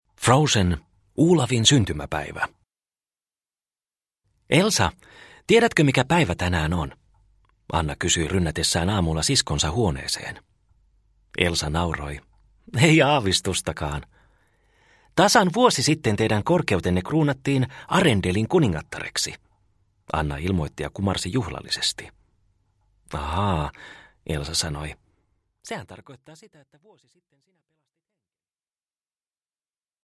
Frozen. Olafin syntymäpäivä – Ljudbok – Laddas ner